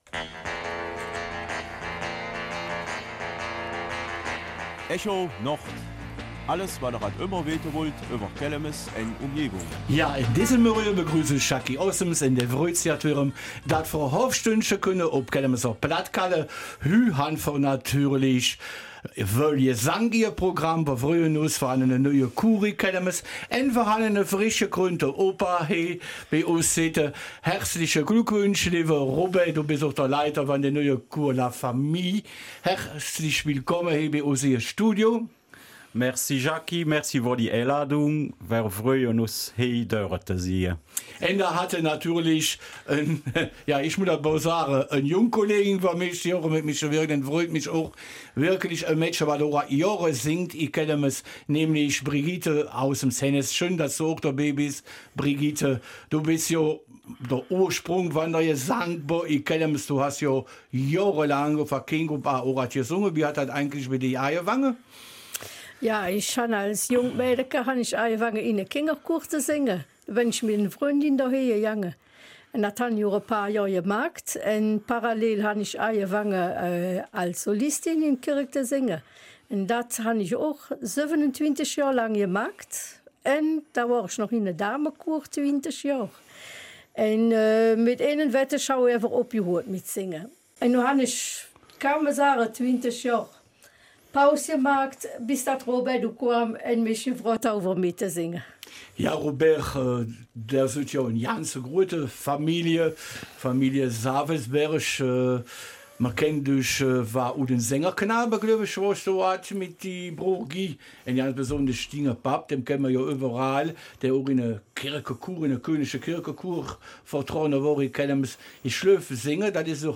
Kelmiser Mundart: Der Chor ''La Familia'' aus Kelmis